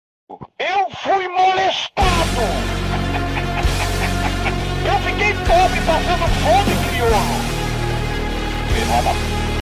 eu fui molestad Meme Sound Effect